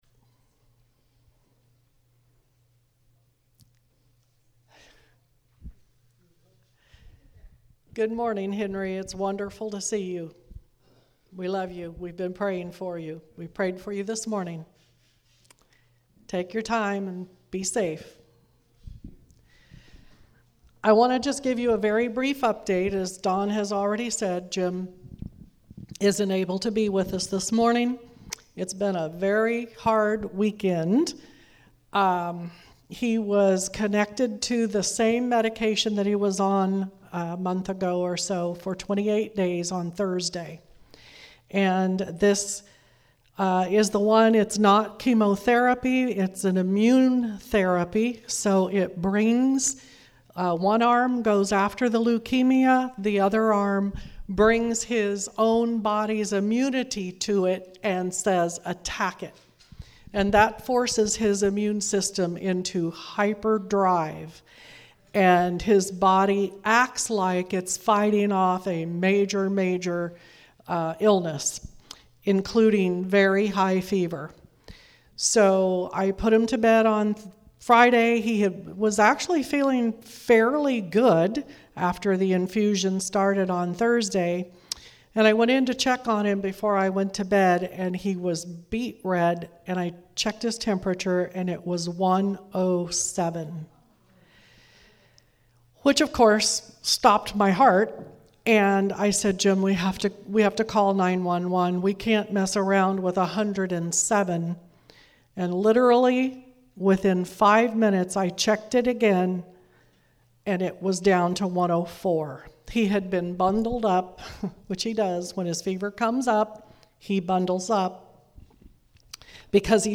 For this service we watched a documentary on the Azusa Street Revival (video here)